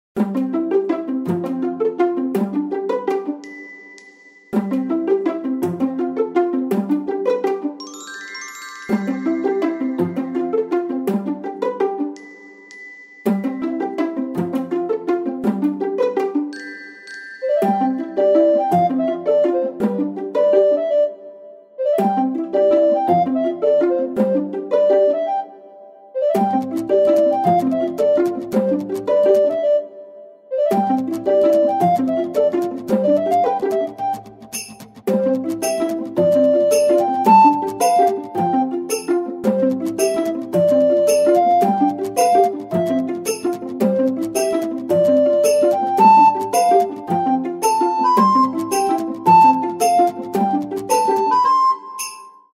フリーBGM その他